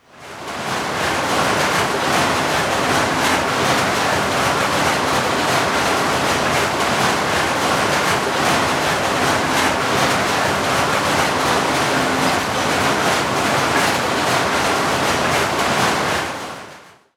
• atmosphere with blanket manufacture machines operating.wav
Atmosphere_with_blanket_manufacture_machines_operating_seq.wav